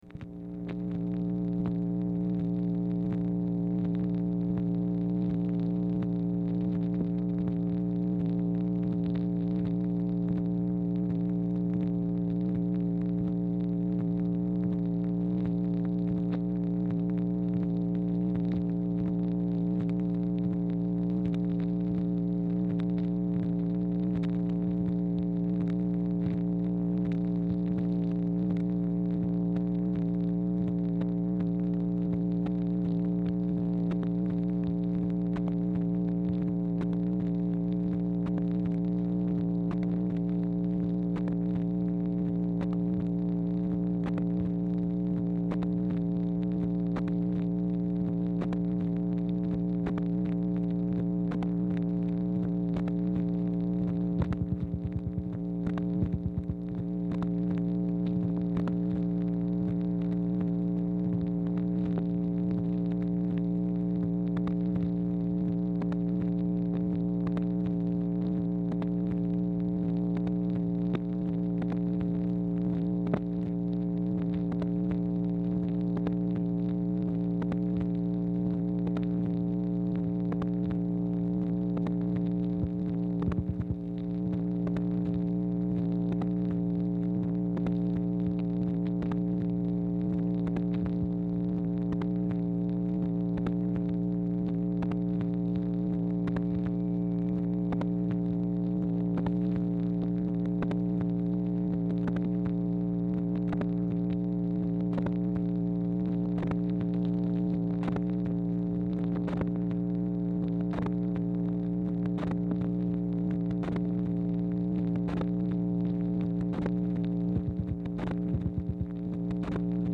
Telephone conversation # 4318, sound recording, MACHINE NOISE, 7/23/1964, time unknown | Discover LBJ
Format Dictation belt
Specific Item Type Telephone conversation